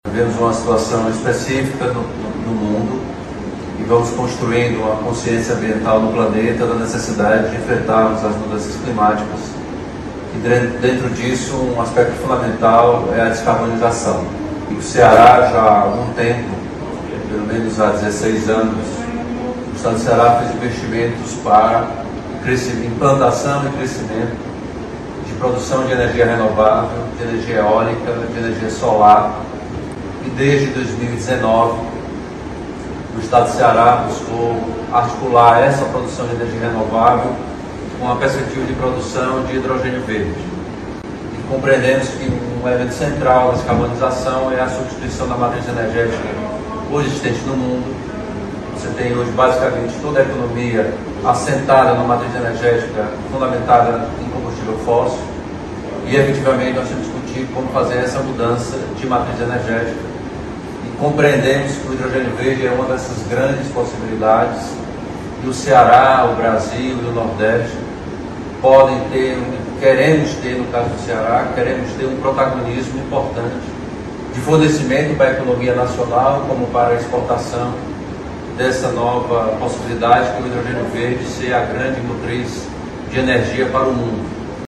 O governador Elmano de Freitas marcou presença na Conferência das Partes (COP) 28, realizada em Dubai, onde participou ativamente da apresentação de um painel.